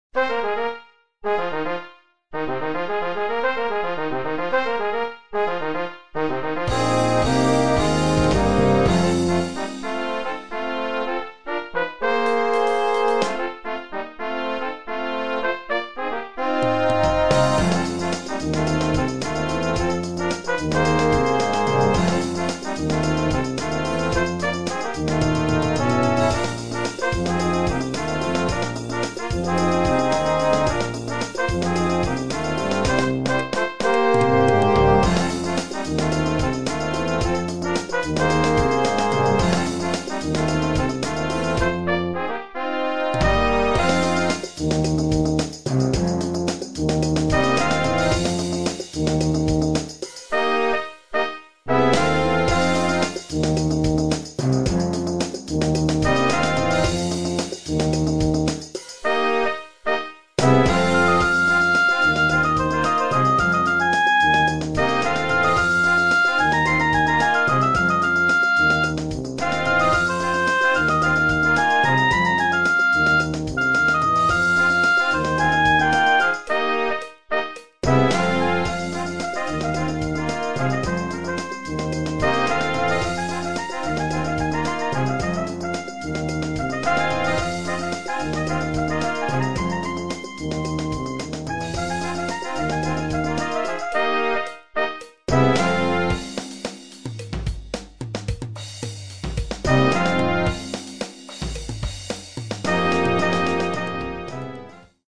Noten für flexibles Ensemble, 4-stimmig + Percussion.